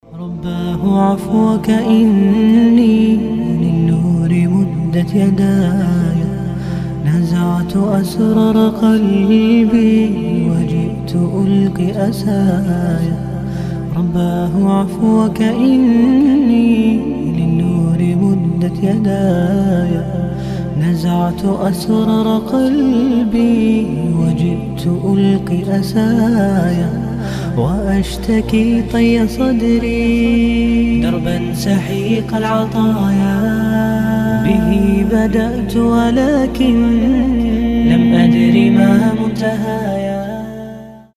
• Качество: 320, Stereo
мужской голос
спокойные
арабские
мусульманские